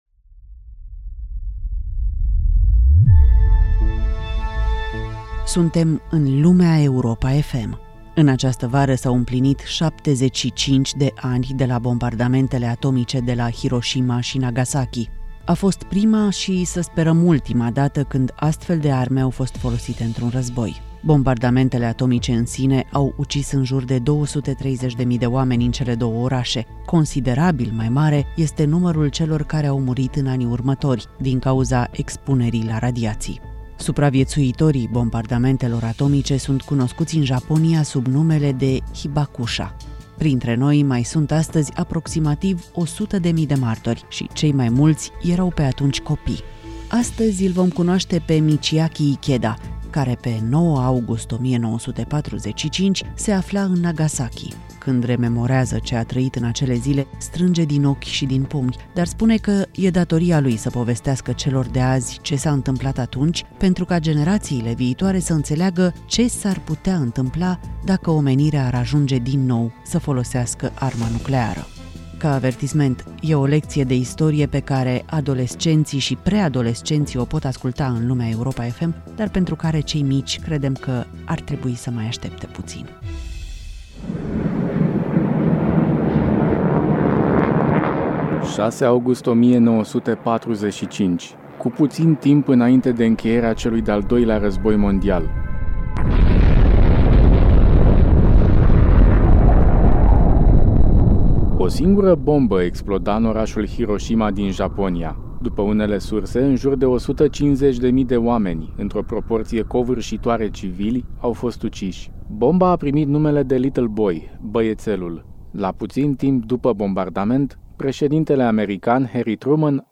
LUMEA EUROPA FM: Interviu cu un supraviețuitor al bombardamentului de la Nagasaki